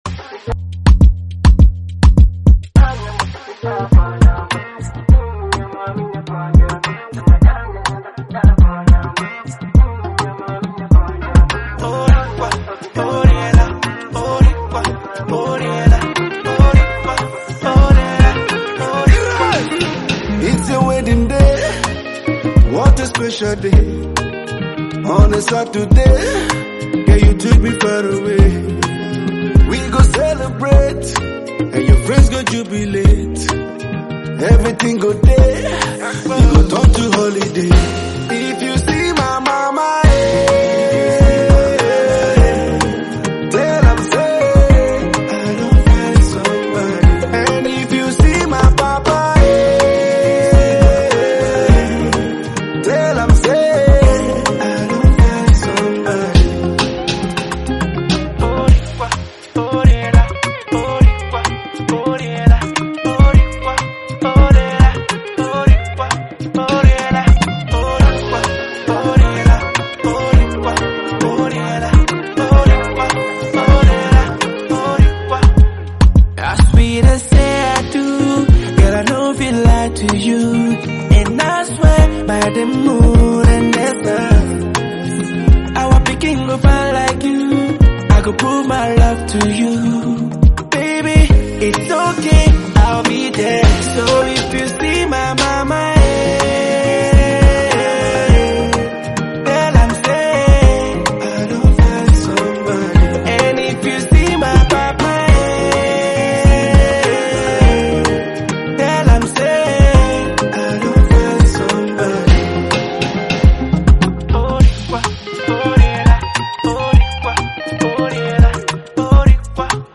Afrobeats
wedding tune